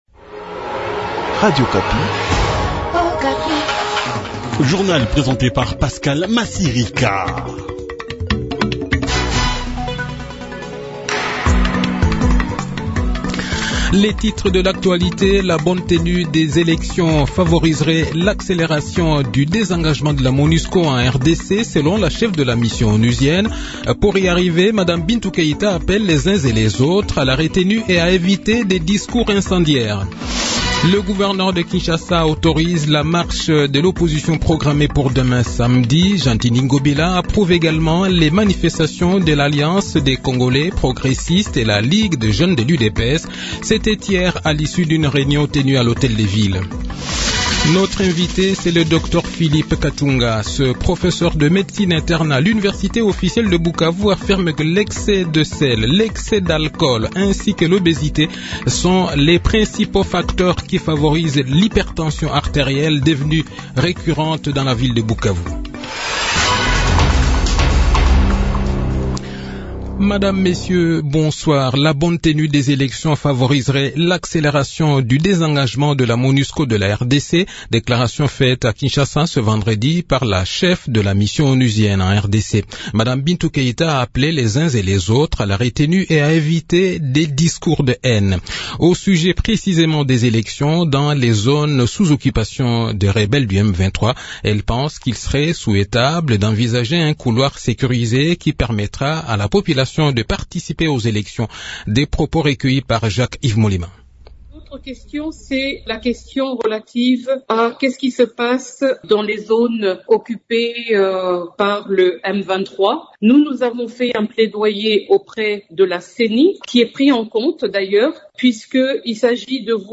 Le journal de 18 h, 19 Mai 2023